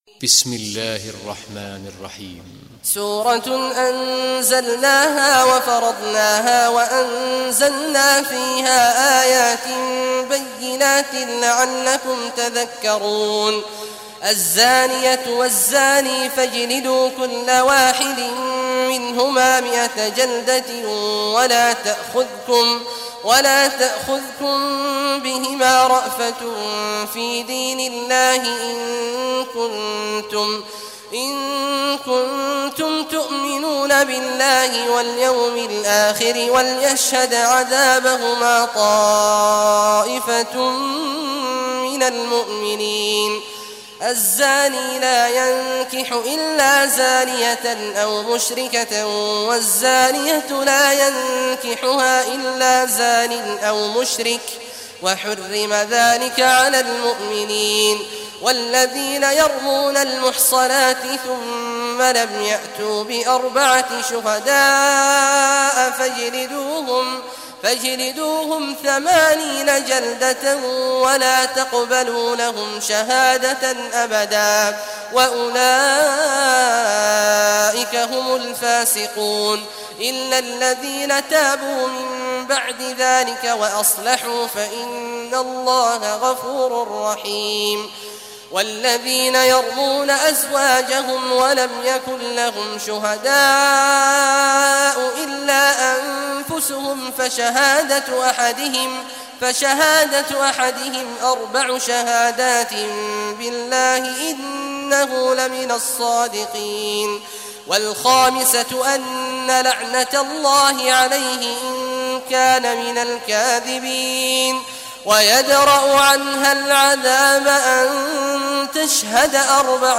Surah Nur Recitation by Sheikh Awad Al Juhany
surah nur, listen or play online mp3 tilawat / recitation in Arabic in the beautiful voice of Sheikh Abdullah Awad al Juhany.